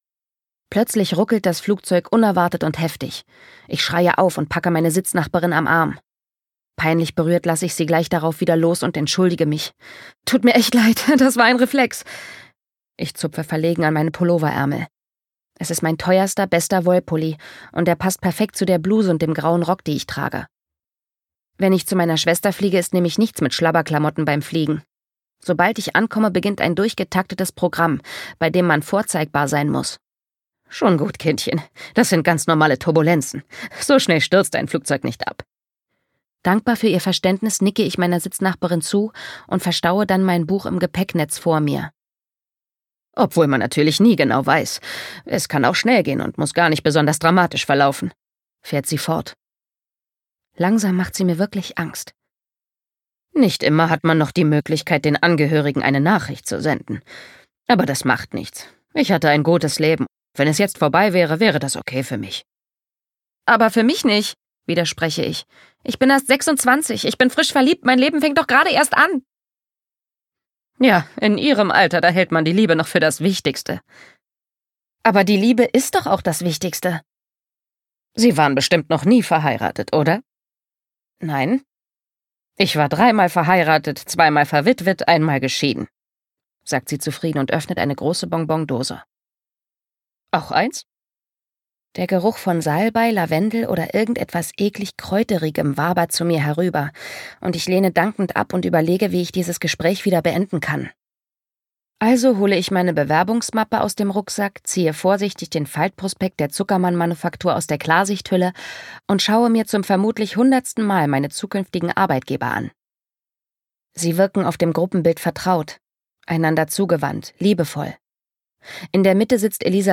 Autorisierte Lesefassung